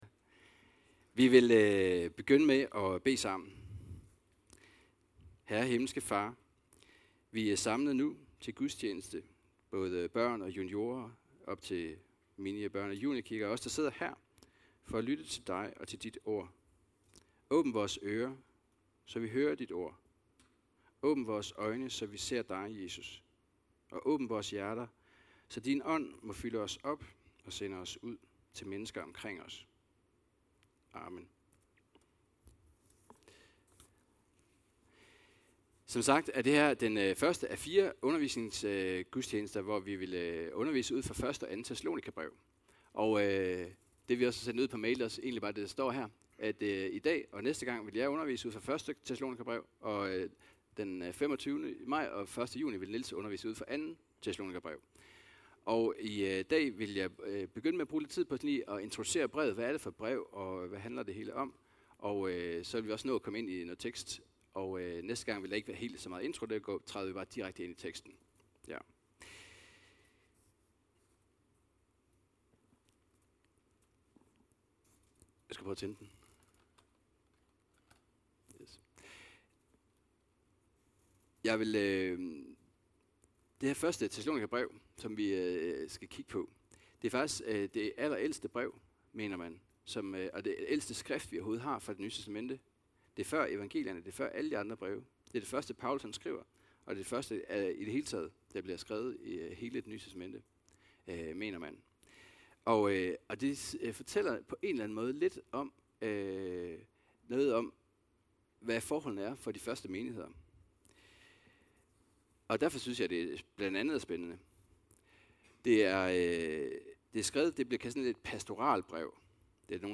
Undervisningsgudstjeneste (del 1 af 4) – 1.thess 4,1-10 – Tro, Kærlighed og Håb